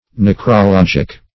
Necrologic \Nec`ro*log"ic\, Necrological \Nec`ro*log"ic*al\, a.